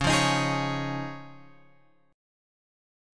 C#add9 chord {9 8 x 8 9 9} chord